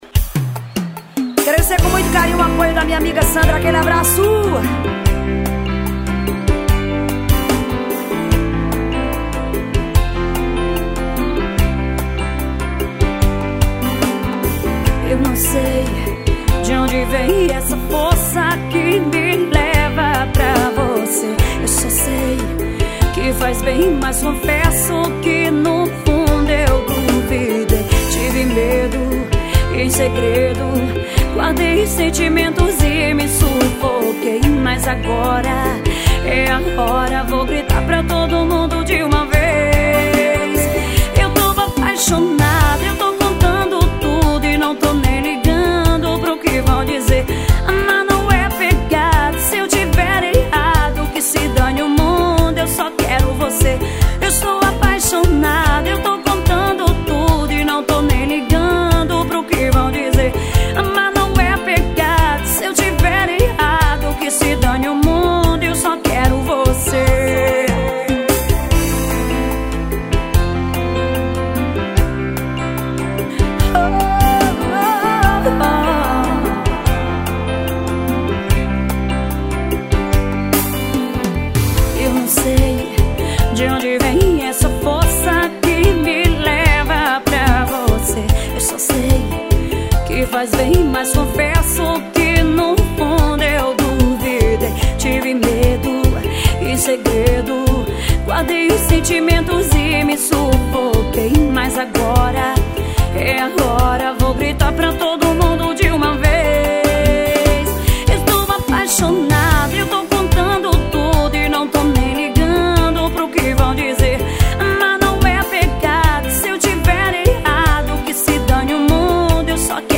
AO VIVO em Porto da Folha-SE.